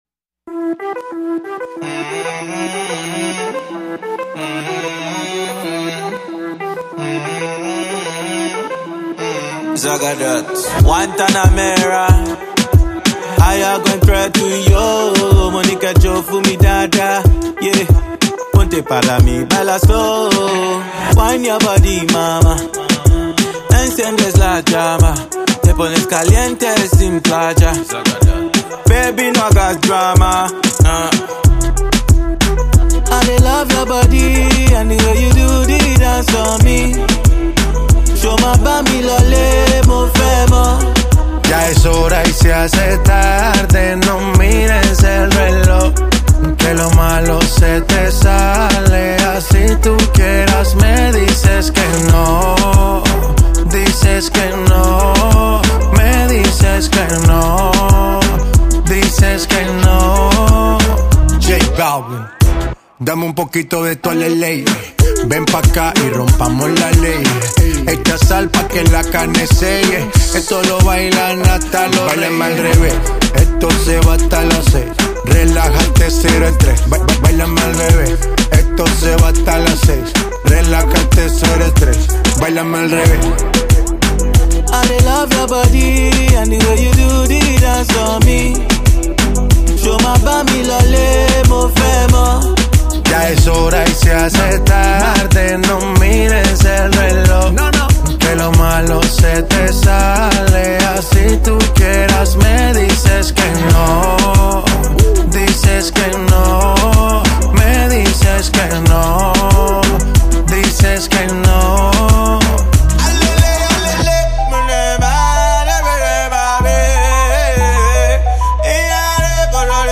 رگیتون